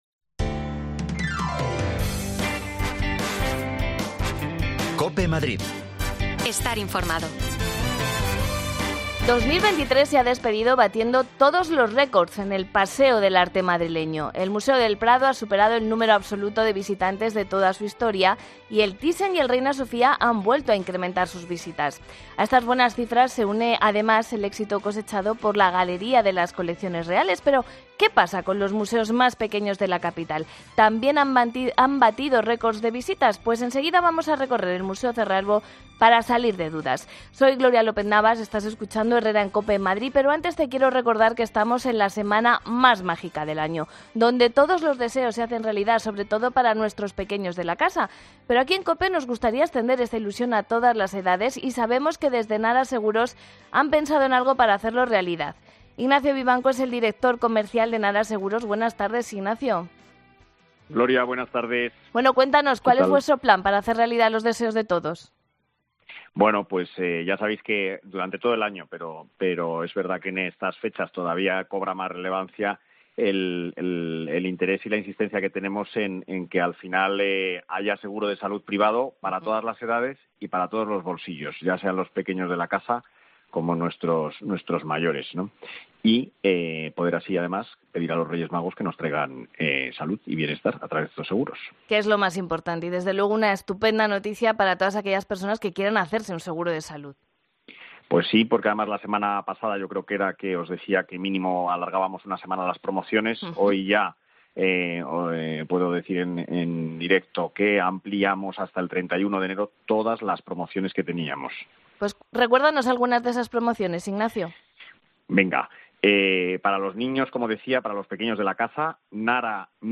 Escucha ya las desconexiones locales de Madrid de Herrera en COPE en Madrid y Mediodía COPE en Madrid.
Te contamos las últimas noticias de la Comunidad de Madrid con los mejores reportajes que más te interesan y las mejores entrevistas, siempre pensando en el ciudadano madrileño.